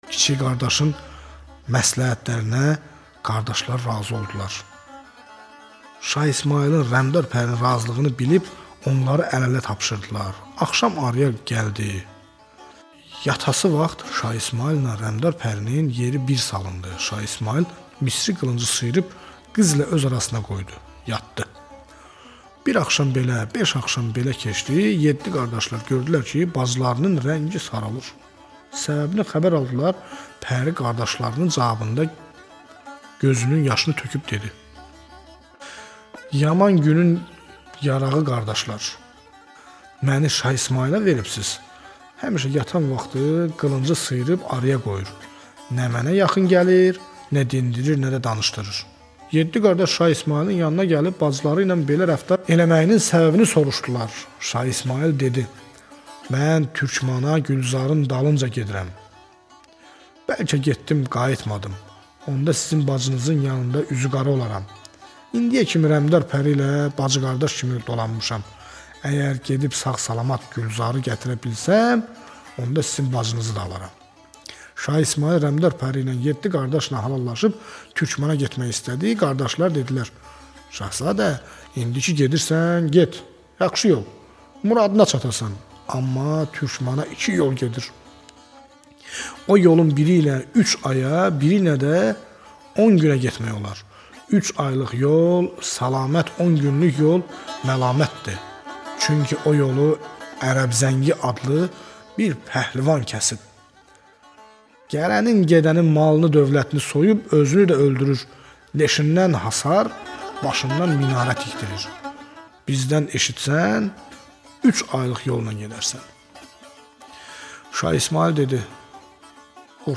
Azeri dastan